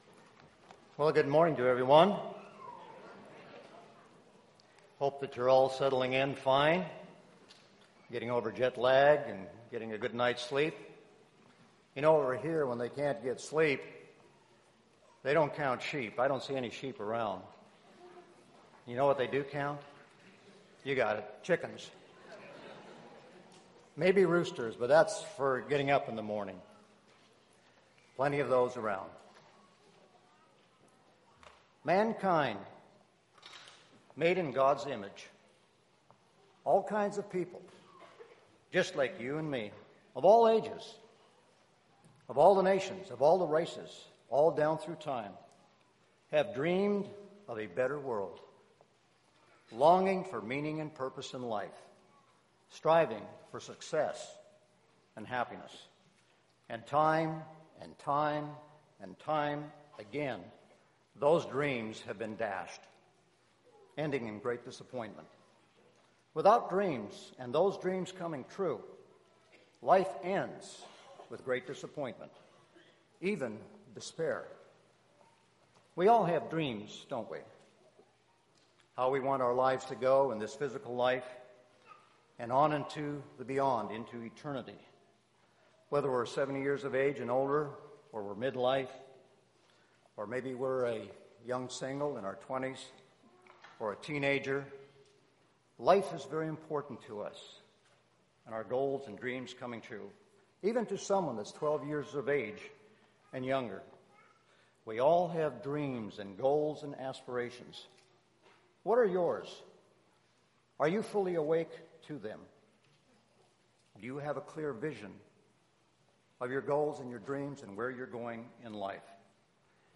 This sermon was given at the Lihue, Hawaii 2014 Feast site.